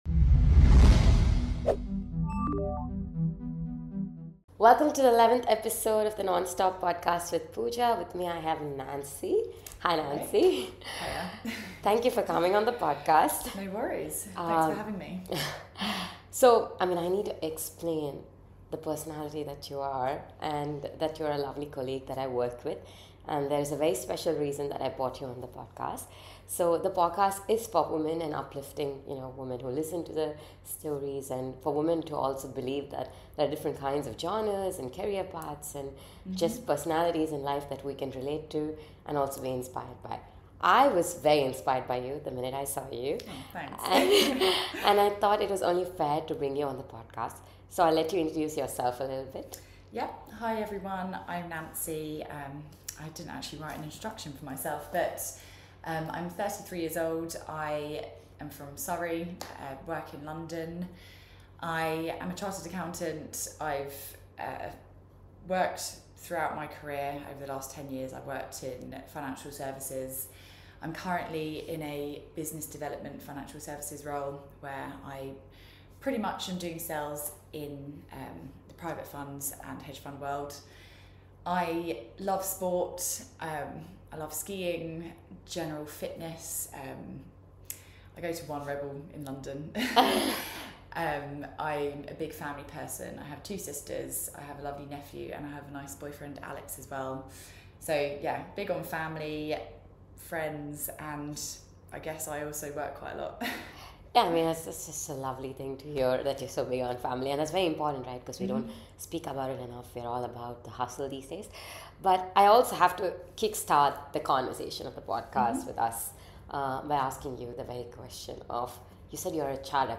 Join us for an insightful conversation about the challenges, strategies, and rewards of working in hedge funds.